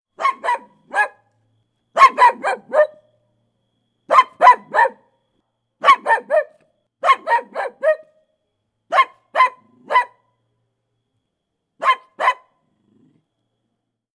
dog-dataset
puppy_0008.wav